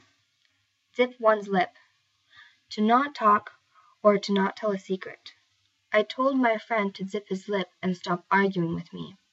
ネイティブによる発音は下記のリンクから聞くことができます。